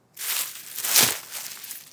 dig_2.wav